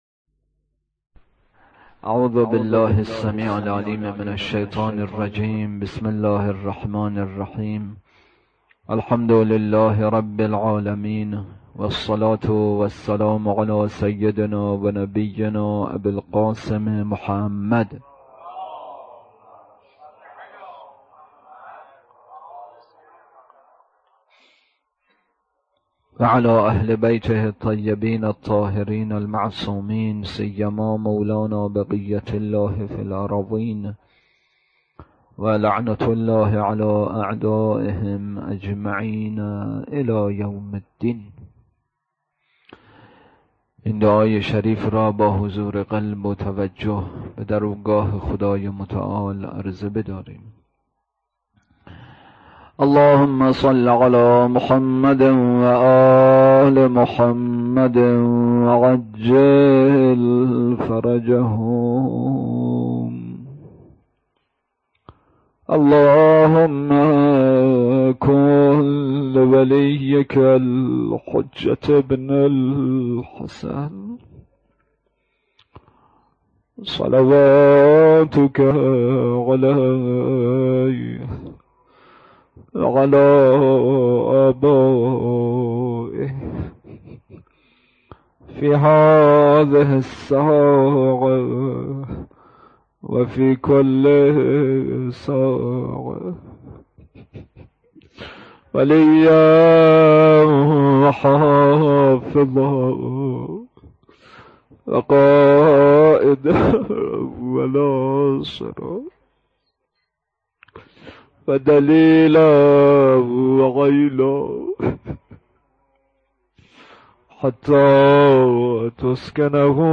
اطلاعات آلبوم سخنرانی